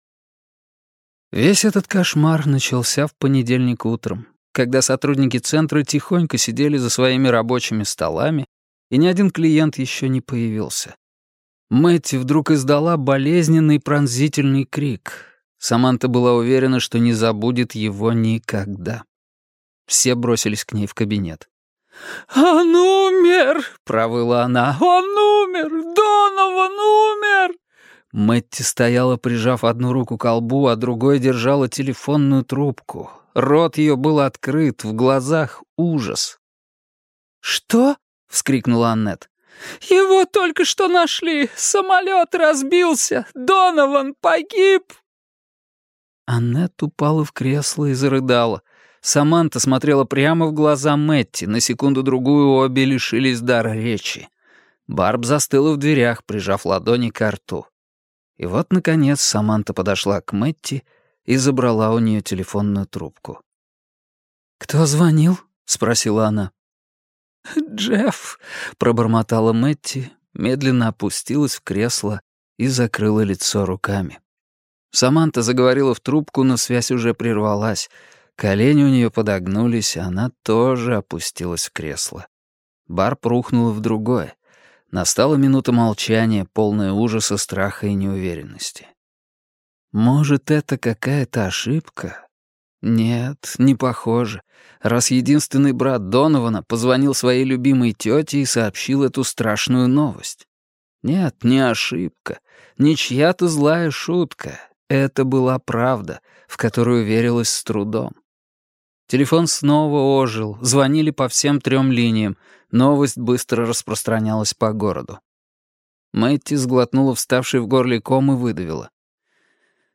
Аудиокнига Серая гора | Библиотека аудиокниг
Прослушать и бесплатно скачать фрагмент аудиокниги